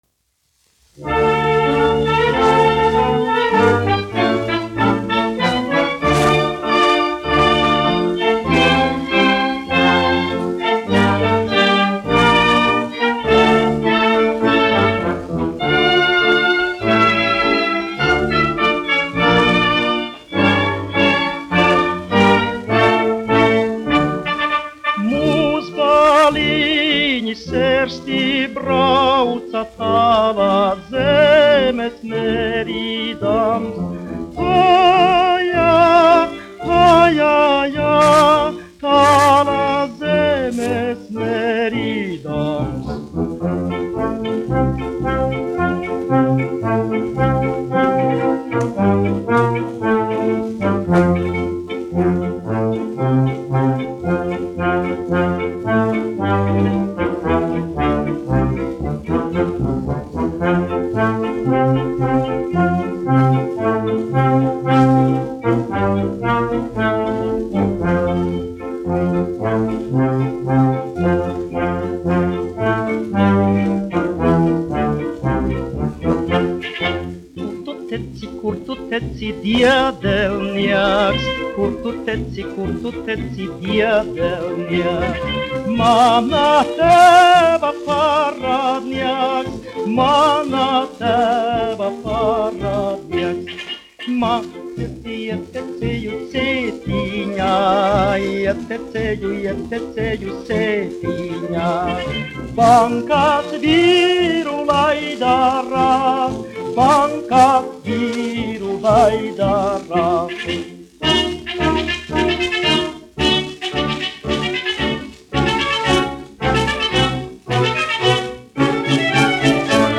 1 skpl. : analogs, 78 apgr/min, mono ; 25 cm
Humoristiskās dziesmas
Skaņuplate
Latvijas vēsturiskie šellaka skaņuplašu ieraksti (Kolekcija)